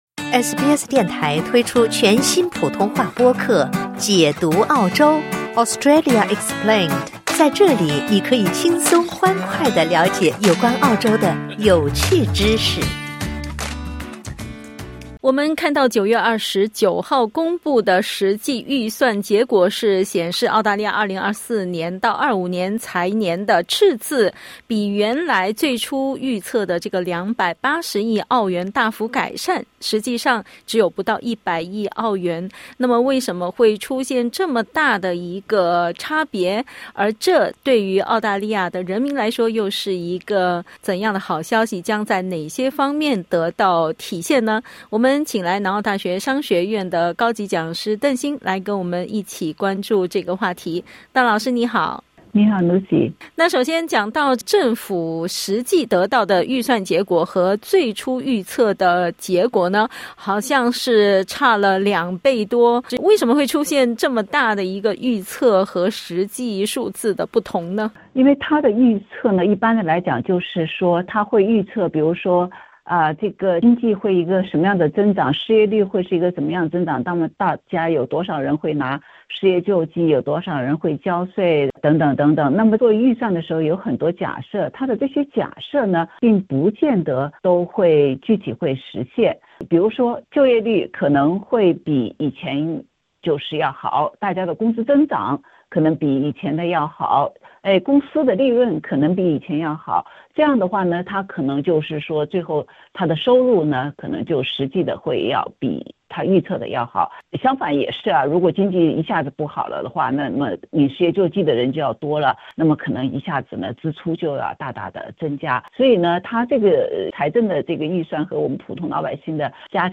（点击音频收听详细采访） 9月29日公布的实际预算结果显示，2024/25财年的赤字不到100亿澳元，较政府最初预测的280亿澳元赤字大幅改善。